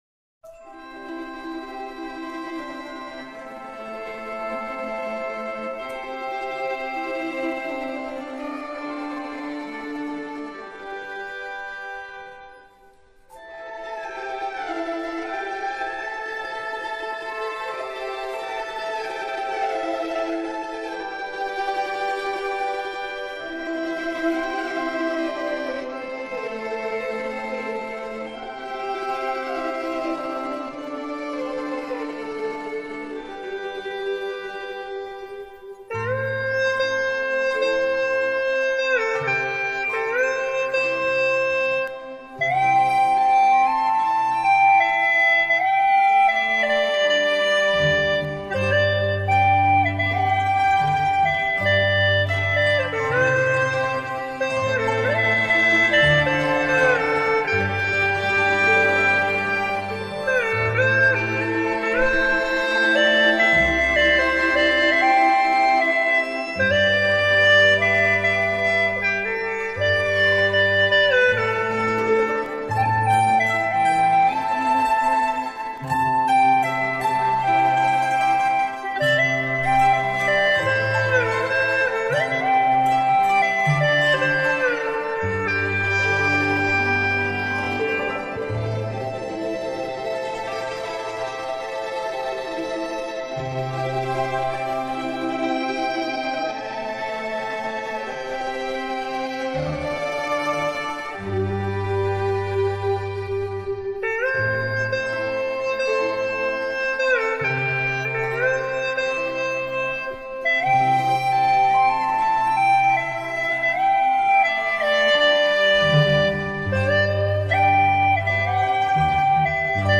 喜欢这首曲，可怎么也吹不出东北的味儿，请老师们多多指教，谢谢!
还可以吧，有东北味道了。
吹的很稳啊。
其实像这样的抒情慢曲难度是很高的，越慢越不容易吹好呢，指颤在这首曲子里基本上用不到了，全靠腹颤呢！
听起来就有点小唢呐的味道，嚎~
这首曲子应该属于那种细腻婉转柔美的风格。